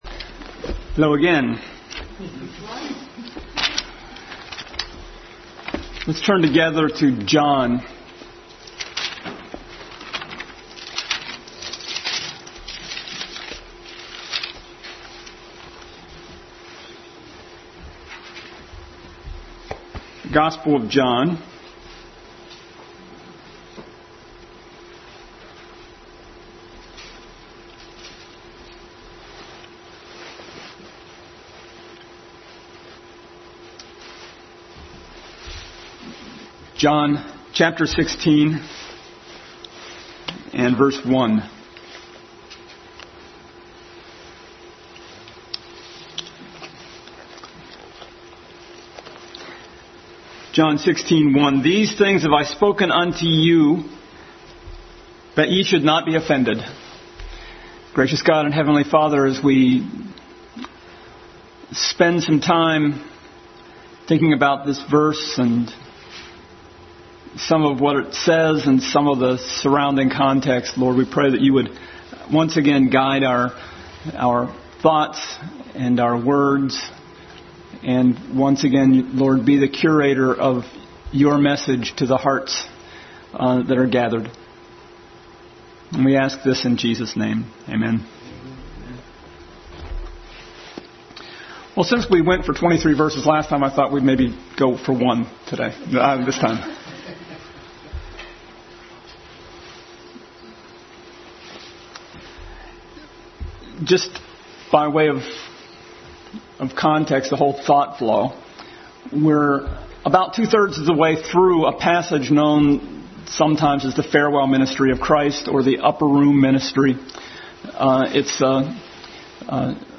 John 16:1 Passage: John 16:1, Isaiah 50:4-7, 52:13-53:7, Acts 7:54-60 Service Type: Family Bible Hour Family Bible Hour Message.